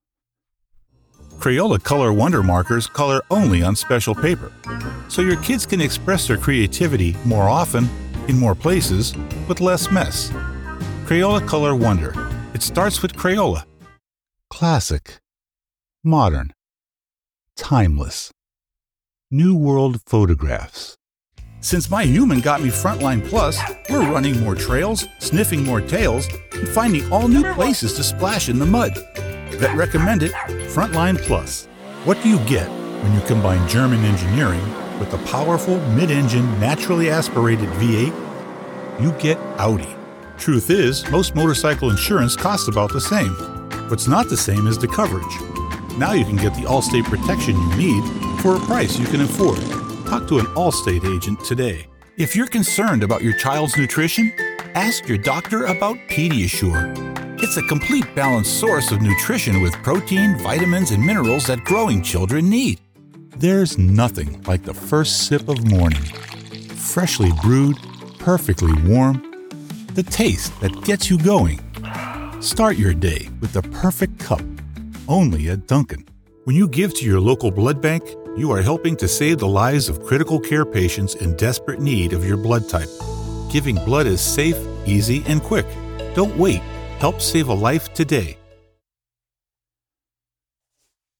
American Voice Over Talent, Artists & Actors
Adult (30-50) | Older Sound (50+)